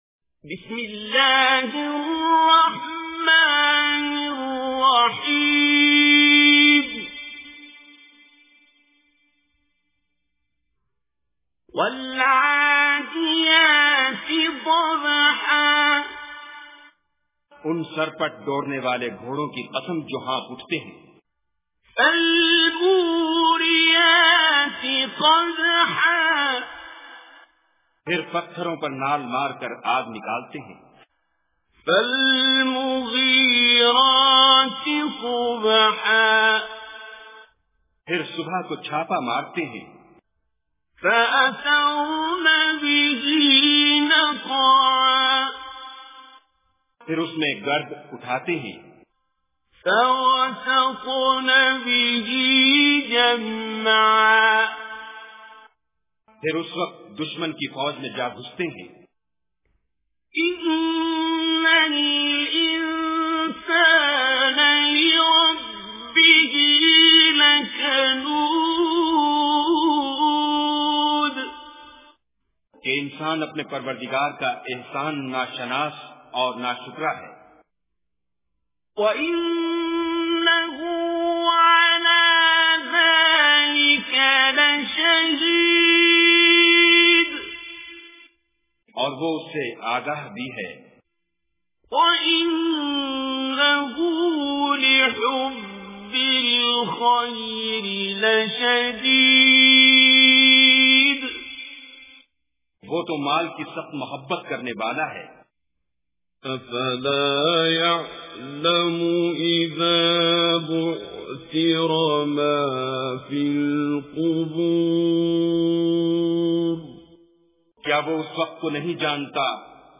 Surah Adiyat Recitation with Urdu Translation
Surah Adiyat, listen online mp3 tilawat / recitation in the voice of Qari Abdul Basit As Samad.